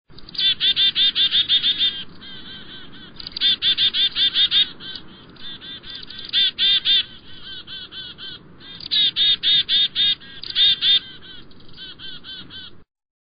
Birdcalls
Chickadee
chickadee.mp3